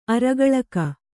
♪ aragaḷaka